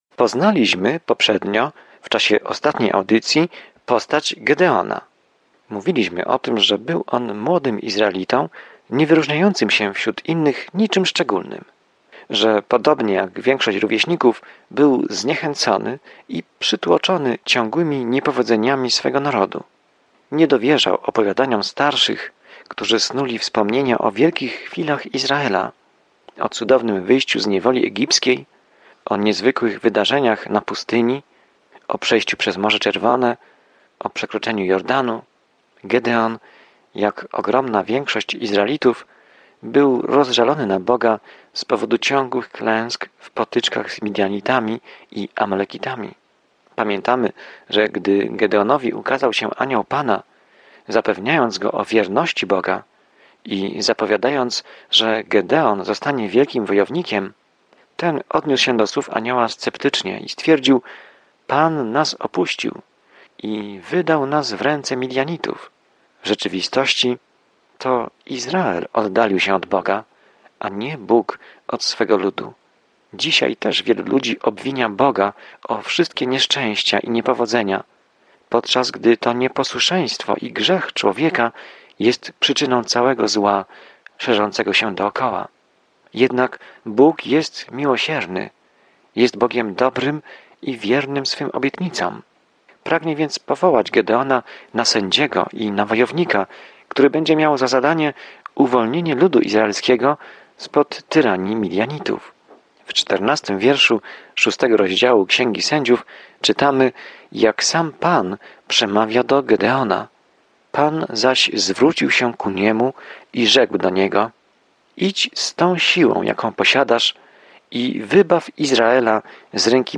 Pismo Święte Sędziów 6:14-40 Dzień 5 Rozpocznij ten plan Dzień 7 O tym planie Sędziowie dokumentują czasami pokręcone i wywrócone do góry nogami życie ludzi rozpoczynających nowe życie w Izraelu. Codziennie podróżuj przez Sędziów, słuchając studium audio i czytając wybrane wersety ze słowa Bożego.